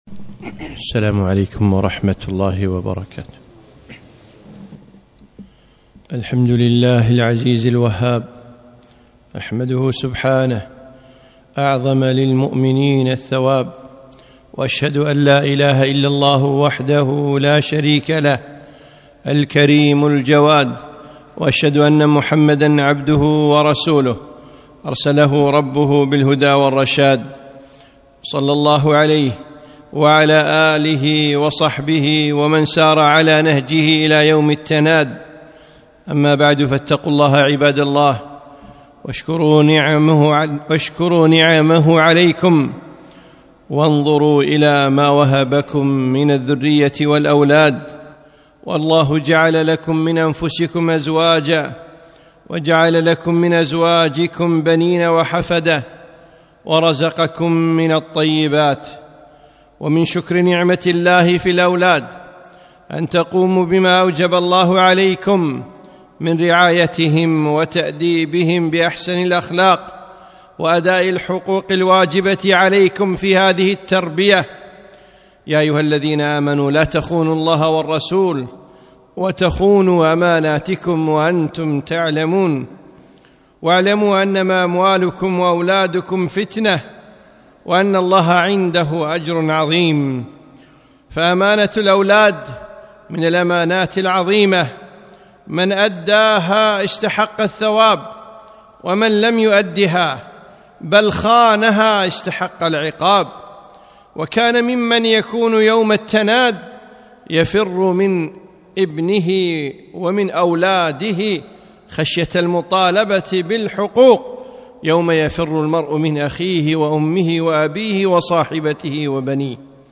خطبة - رَبِّ أولادك